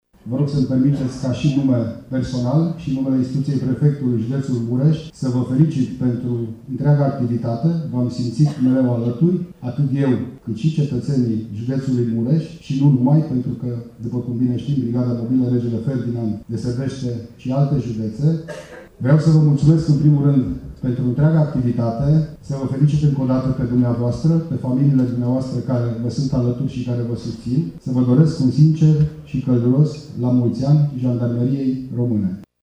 La Tg.Mureș, cei 167 de ani de existență a Jandarmeriei Române au fost marcați azi prin manifestări cu caracter festiv.
La rândul său, prefectul de Mureș a ținut să le adreseze și în nume personal urări de Ziua Armei:
prefect.mp3